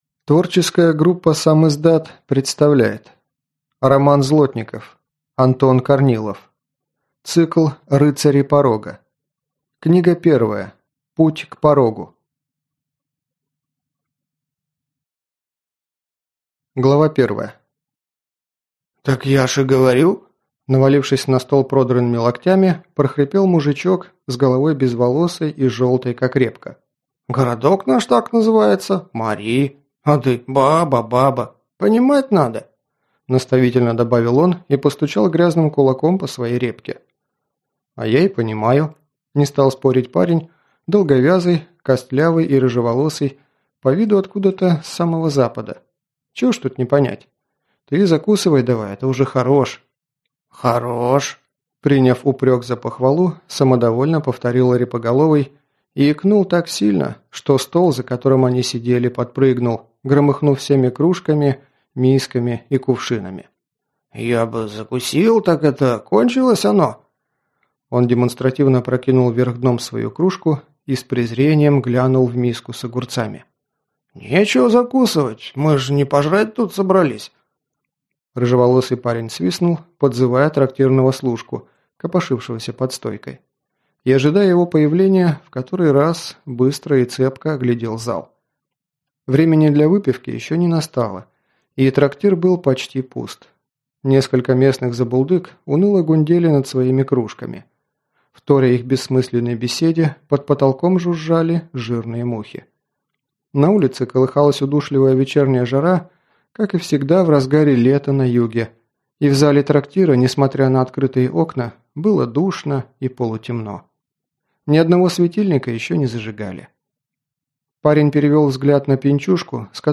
Аудиокнига Путь к Порогу | Библиотека аудиокниг